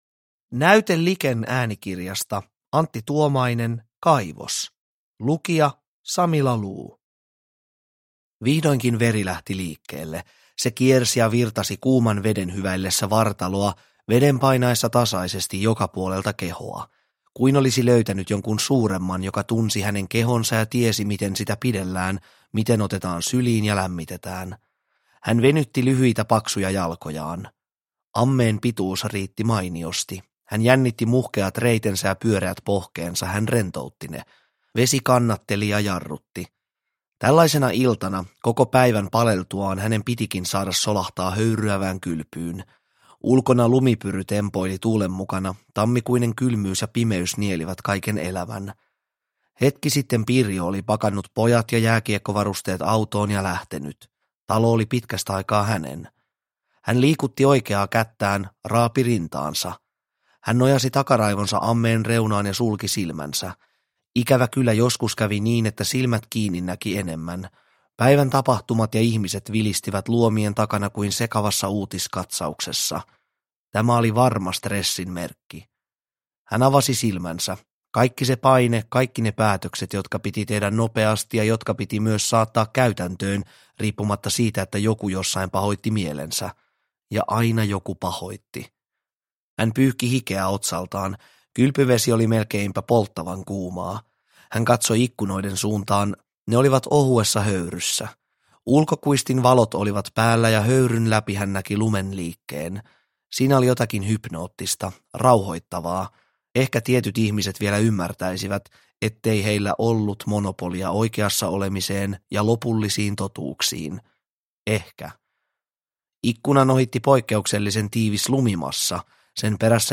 Kaivos – Ljudbok – Laddas ner